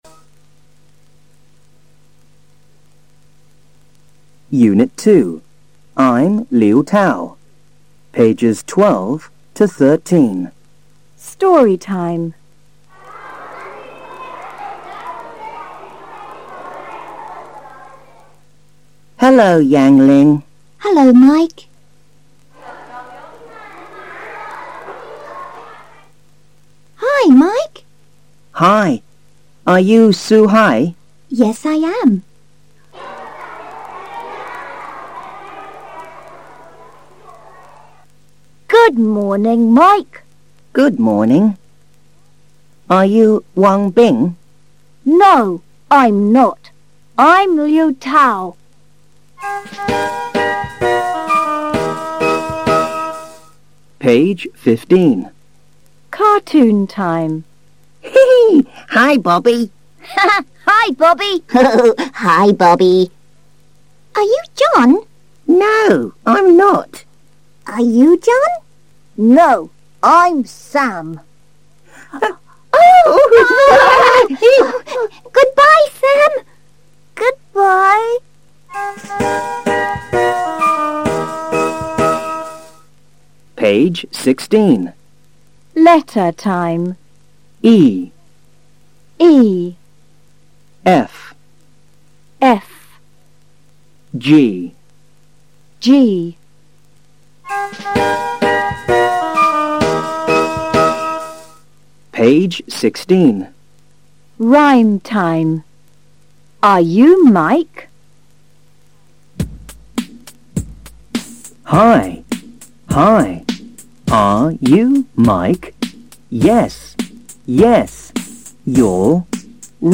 三年级英语上Unit 2 课文.mp3